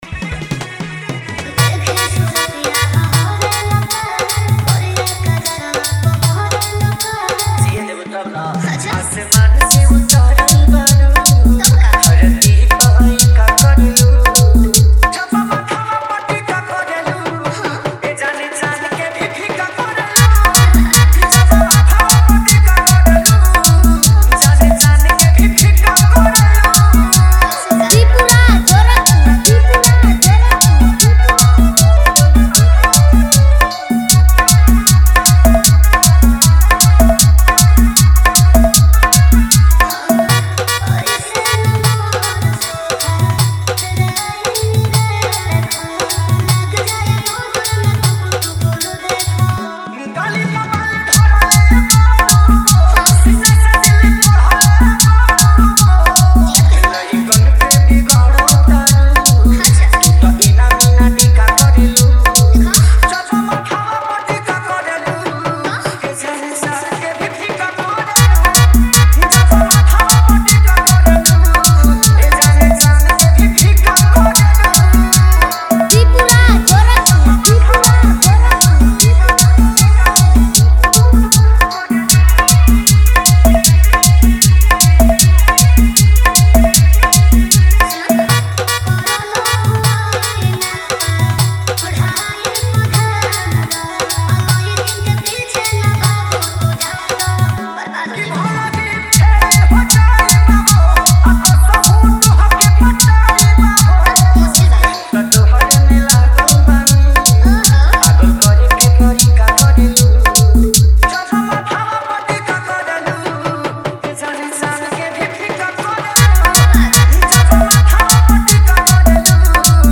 Bhojpuri Dj Songs